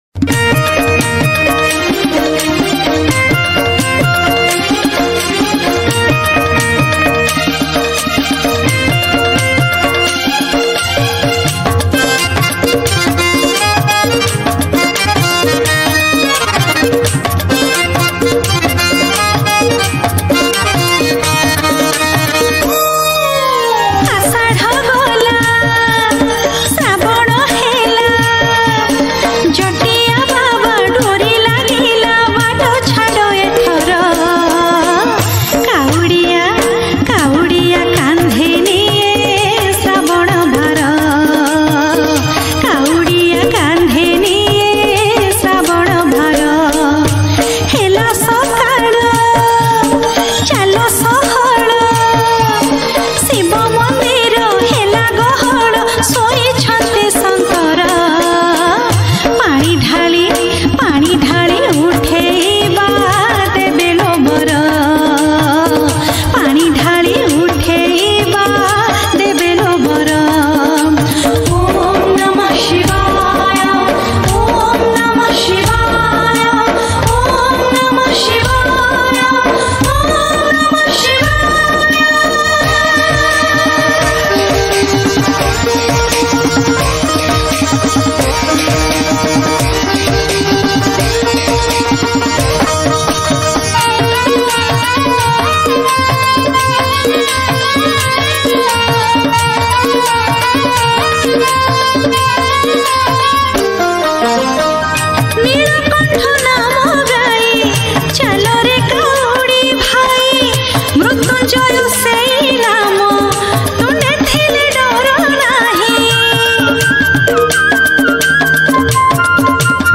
Odia Bolbom Song